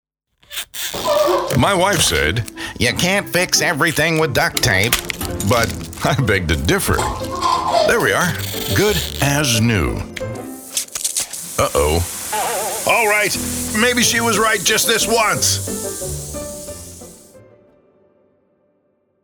English (American)
Commercial, Distinctive, Versatile, Warm, Corporate
He records from a professionally appointed home studio for clients across the globe.